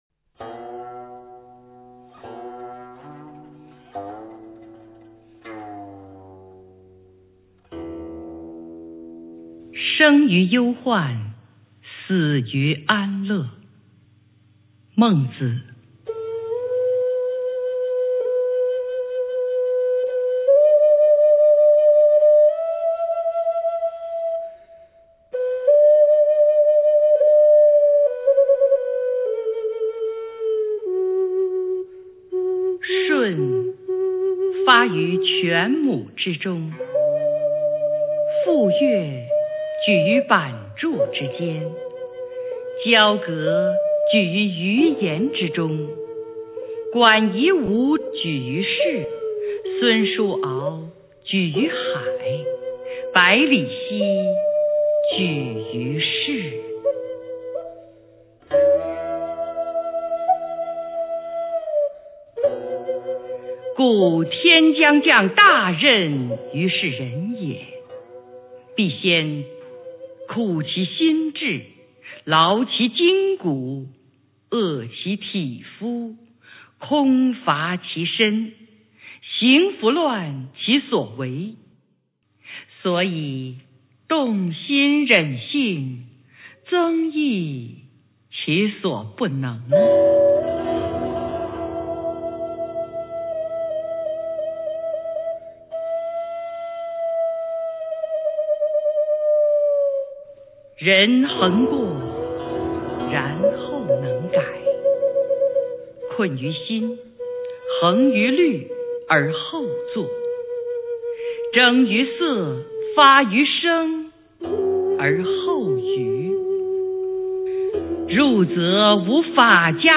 《生于忧患，死于安乐》原文及译文（含朗读）　/ 孟子